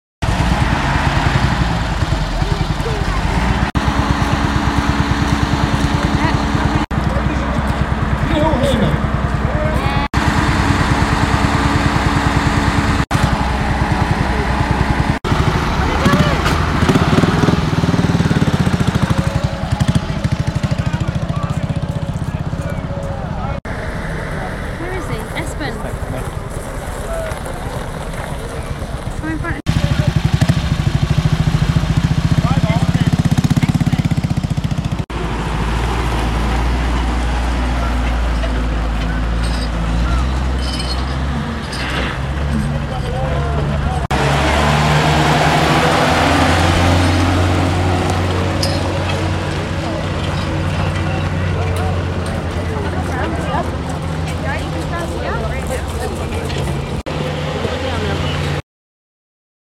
Fort Nelson fire the guns sound effects free download